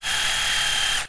auto_first_barrel_spin3.wav